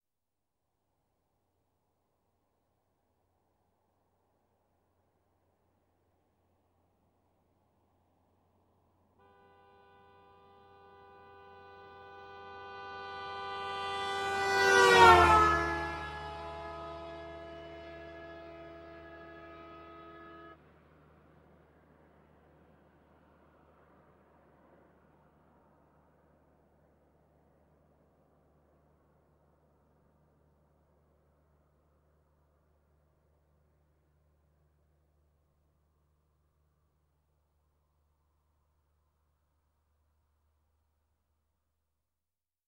Звуки дорожного движения
Громкий звук сигнала при движении